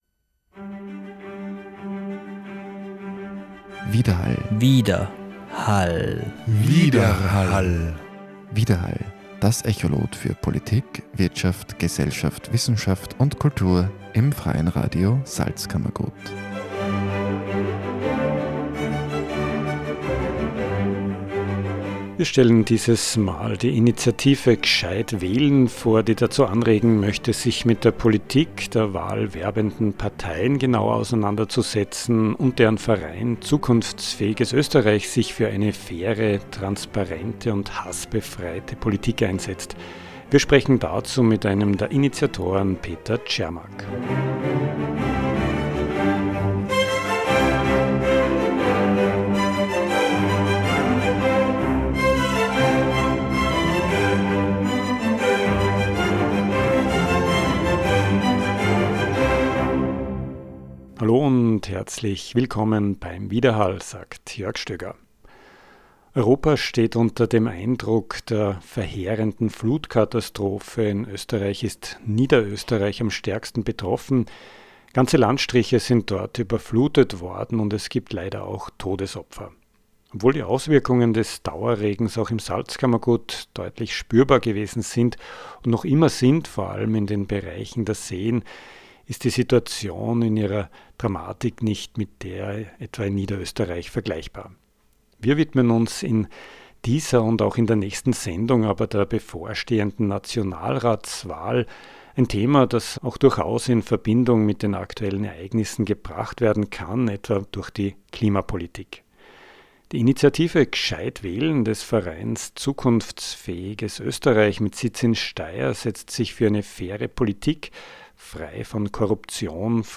Das Interview hat noch vor der Flutkatastrophe stattgefunden.